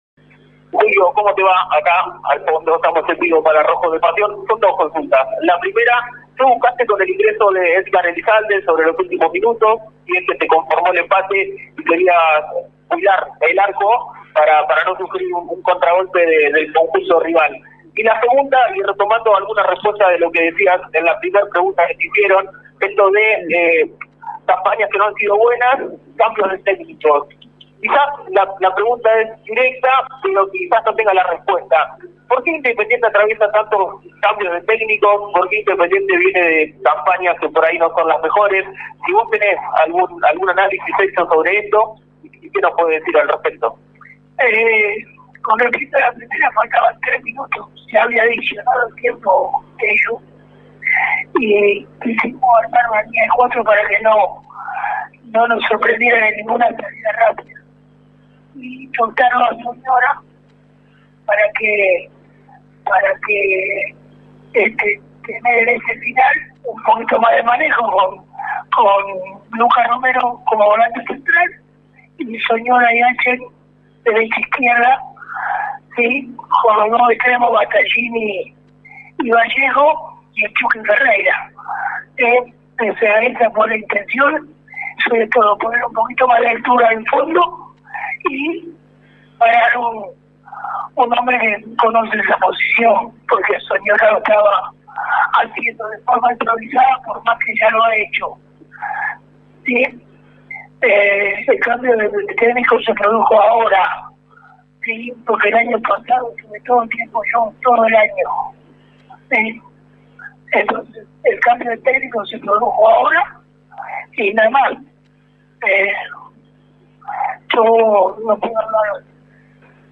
Luego del empate de Independiente ante Lanús por 1-1 en La Fortaleza, el entrenador Julio Falcioni dialogó con la prensa y apuntó fuertemente contra el exmánager, el Rolfi Montenegro, al quejarse por cómo está armado el plantel.